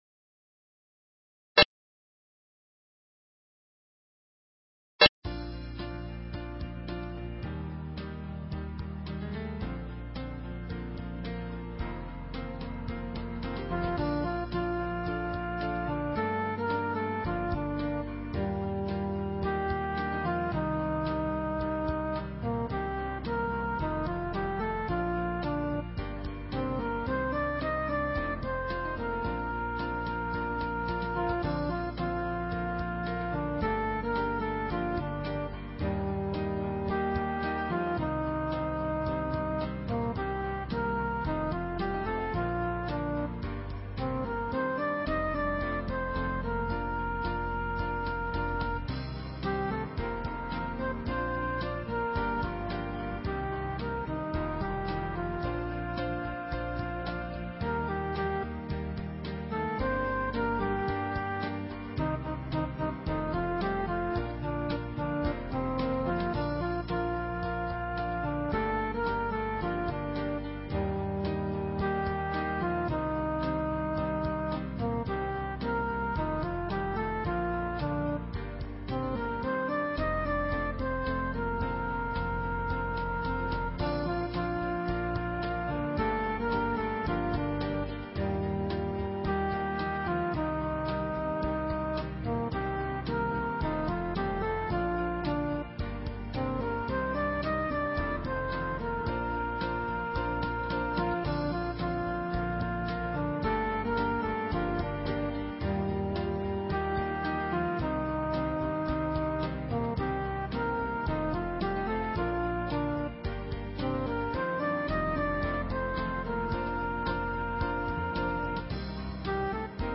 Talk Show Episode, Audio Podcast, Forum For World Peace and Courtesy of BBS Radio on , show guests , about , categorized as